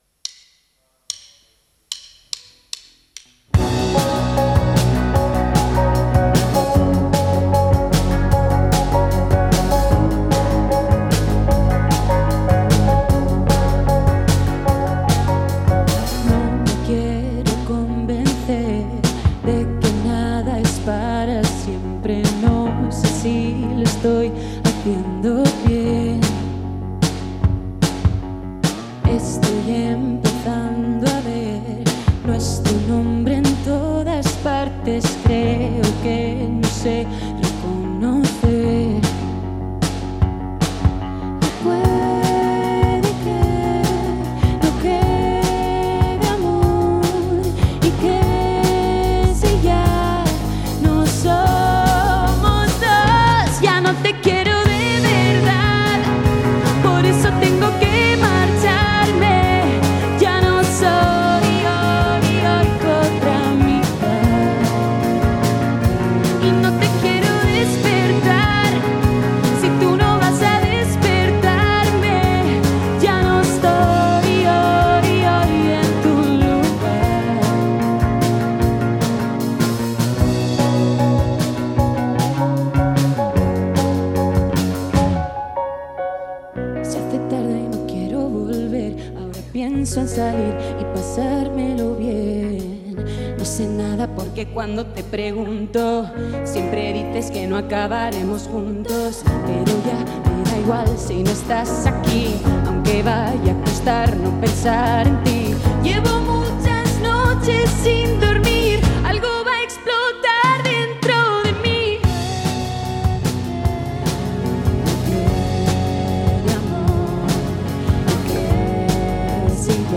A warm musical breeze tonight.
An embrace of heartfelt honesty and genuine warmth.